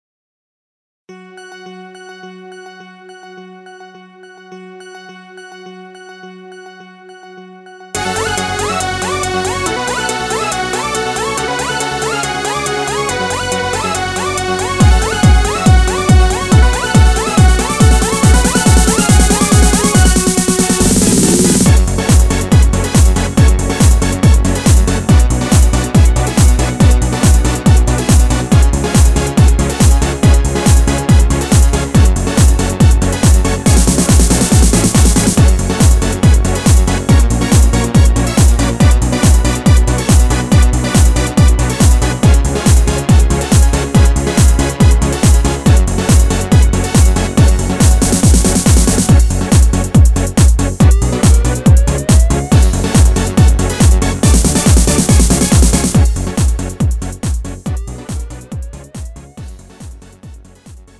음정 (-2)
장르 가요 구분 Lite MR